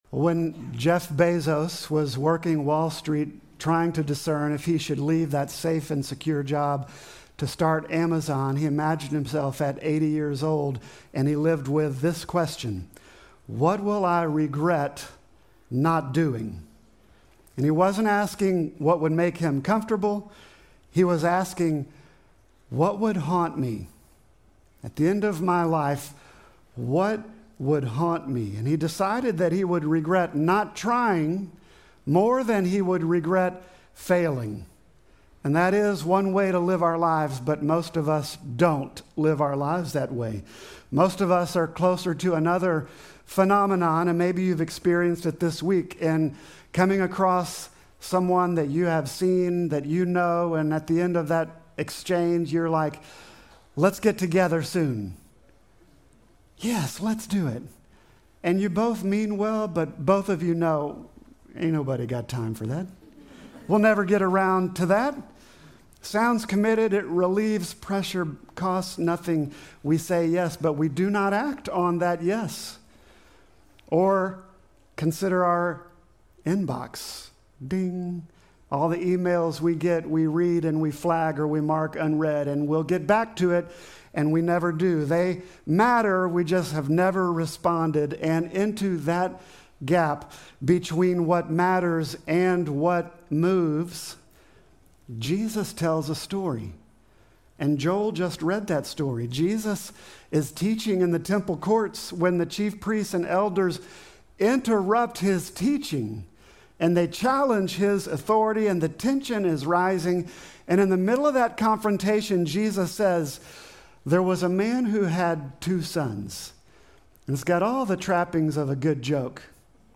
Sermon text: Matthew 21:28